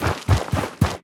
biter-walk-big-5.ogg